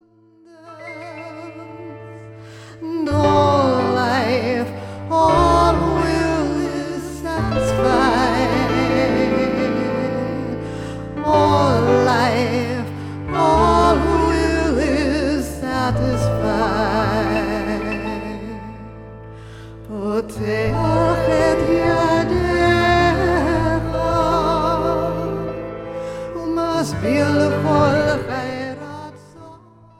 Through meditative chant and spiritual melody and text